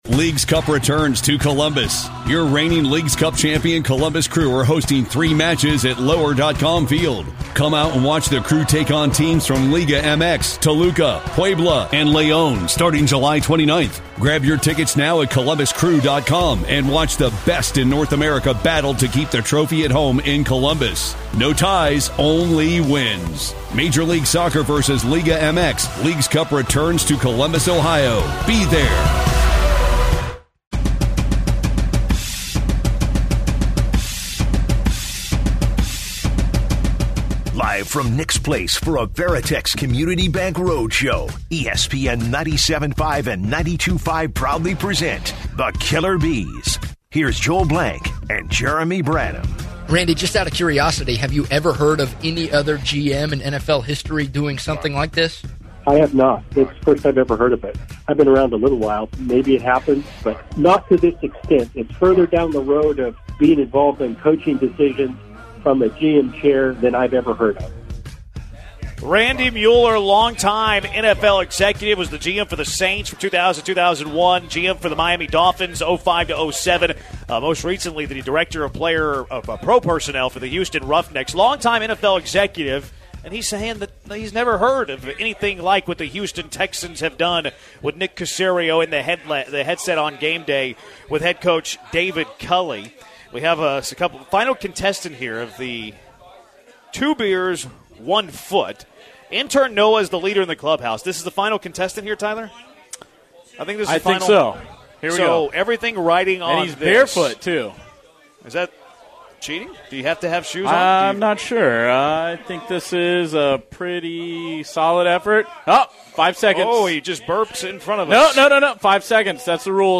Live from Nick’s Place for the station Christmas Party, the guys continue the Caserio discussion, look ahead to all the weekend NFL games they have yet to hit on, debate the best fits for Carlos Correa, and finish things with a fantastic wing eating contest.